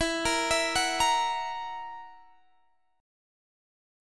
Esus2b5 Chord
Listen to Esus2b5 strummed